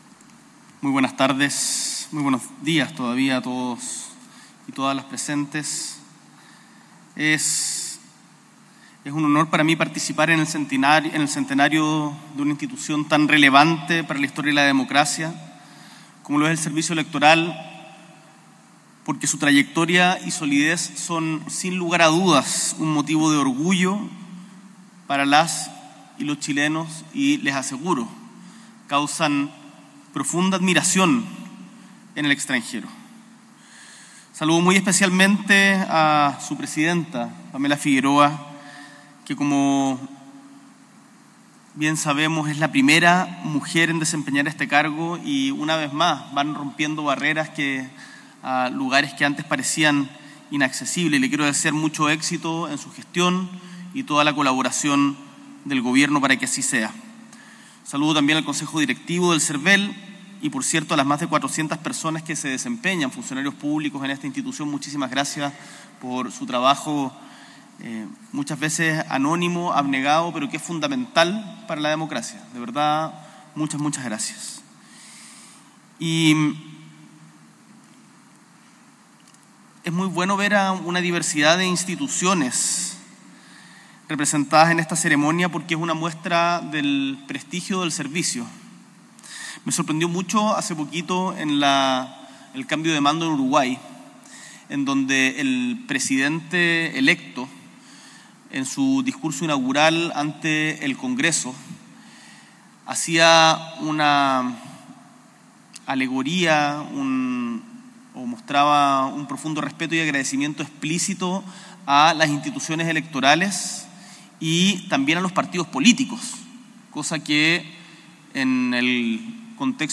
S.E. el Presidente de la República, Gabriel Boric Font, asiste a la conmemoración de los 100 años del Servel
Discurso